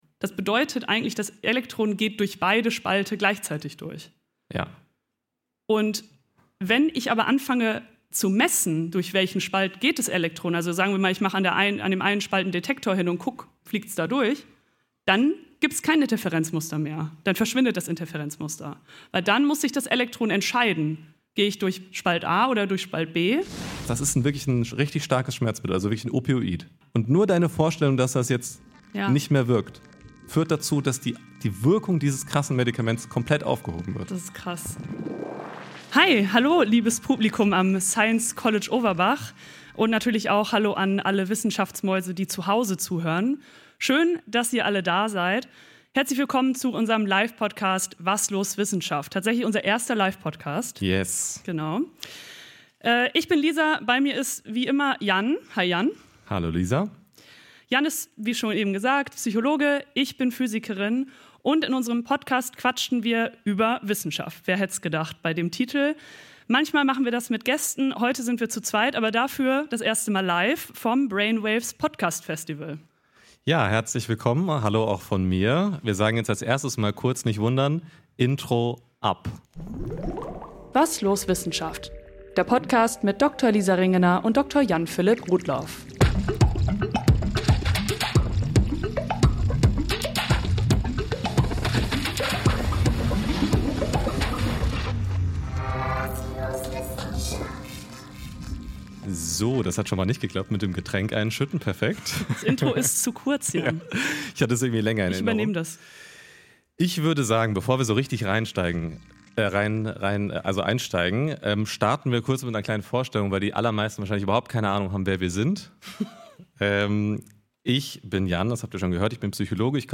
Mit echtem Publikum, echter Aufregung und einem echten Laser!